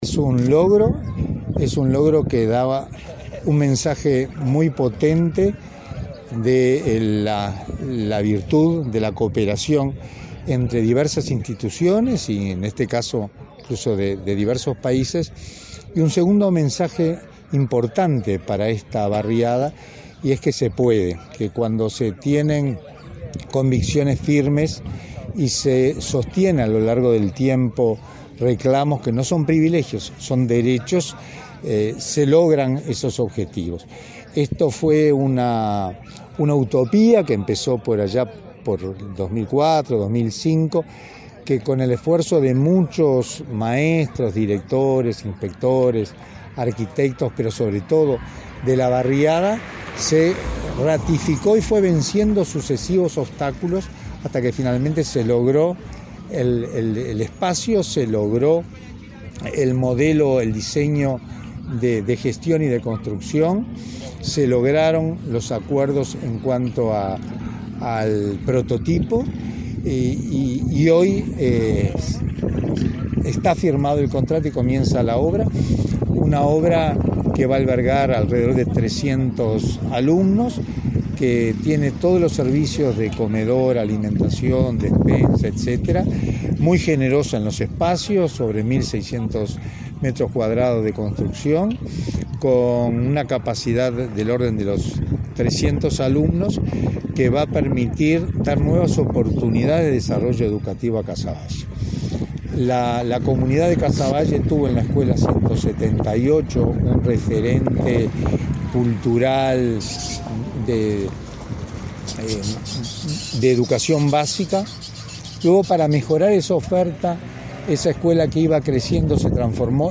“En un logro que da un mensaje potente de la virtud de la cooperación entre instituciones y países”, afirmó el consejero de Primaria Héctor Florit, en el lanzamiento del proyecto de la escuela de tiempo completo n.° 319 República Popular China, en el barrio Casavalle, que será construida por una donación del país asiático de US$ 2 millones.